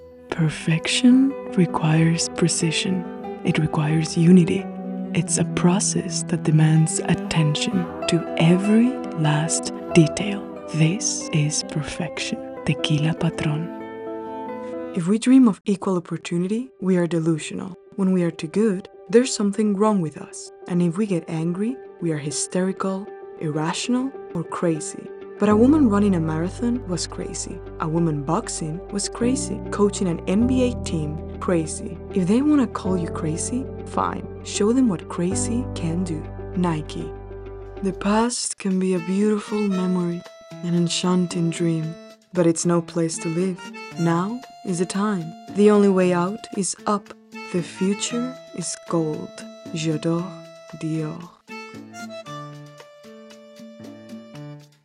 Voiceover Reel
5833-voiceover-reel